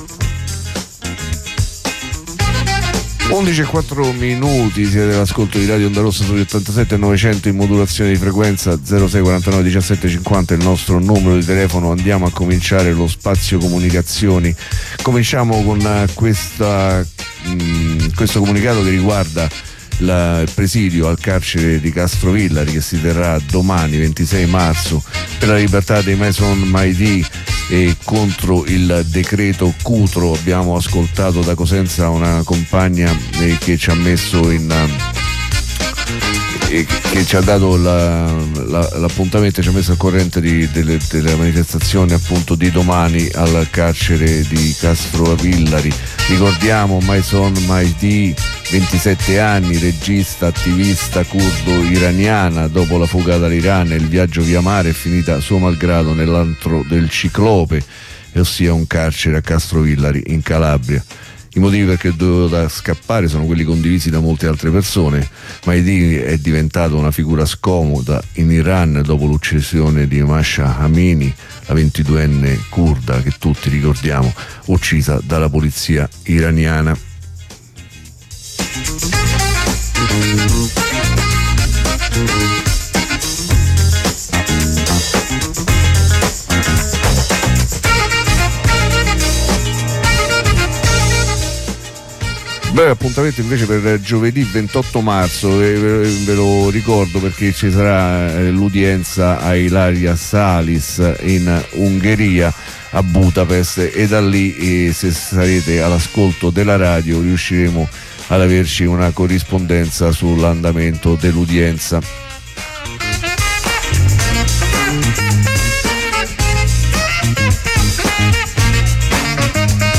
Corrispondenza dalla piazza di Cagliari